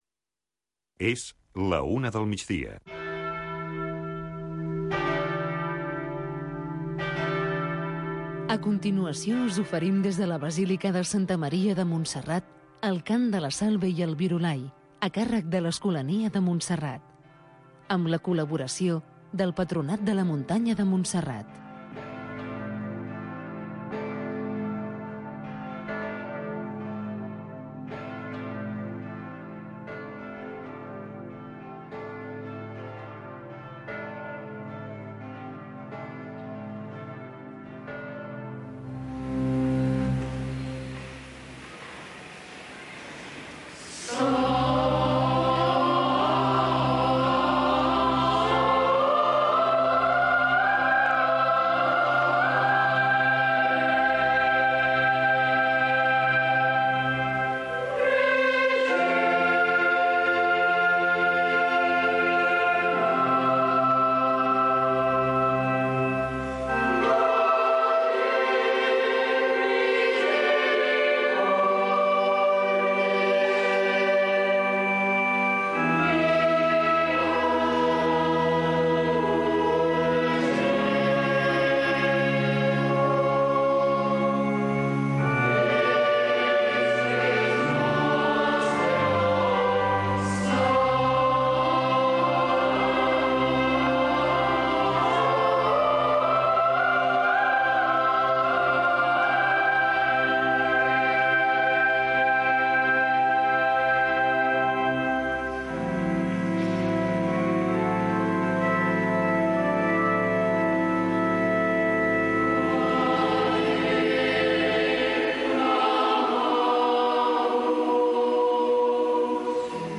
amb l'Escolania de Montserrat
L’escolania de Montserrat canta la Salve i el Virolai als peus de la Moreneta, com un moment de pregària al migdia, en el qual hi participen molts fidels, pelegrins i turistes que poden arribar omplir a vessar la basílica.